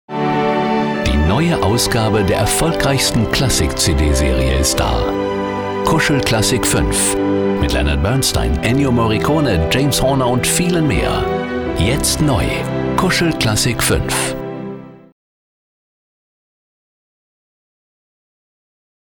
Profi-Sprecher deutsch. Warme freundliche Stimme, Imagefilme, Dokumentationen
norddeutsch
Sprechprobe: Werbung (Muttersprache):
german voice over artist